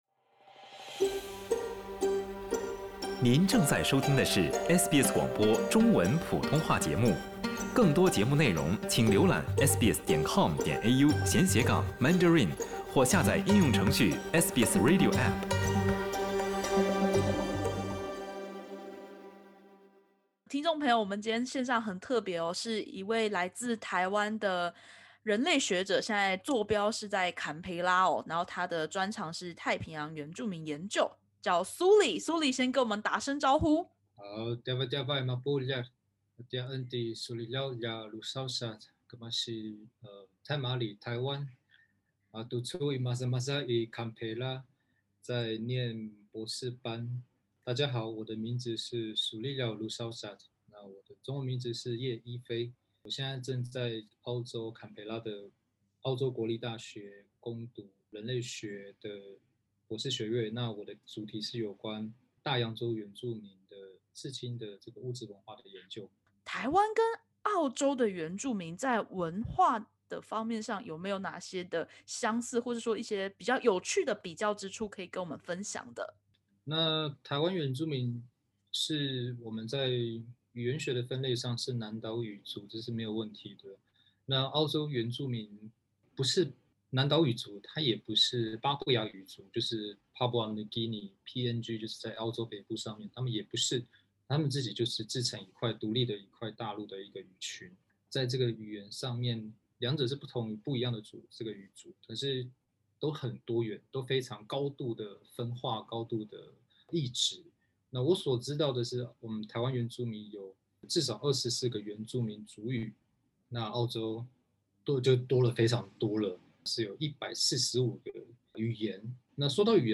地理距离遥远的澳洲和台湾，两地的原住民文化竟有许多对话空间？原住民外交如何落实交流？点击首图收听采访音频。